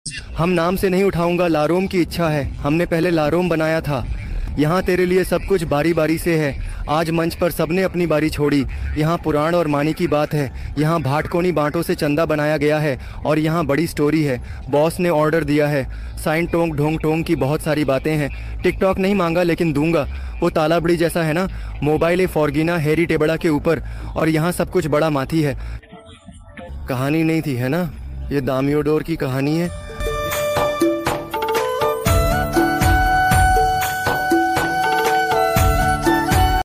AI Dubbing Hindi // Sound Effects Free Download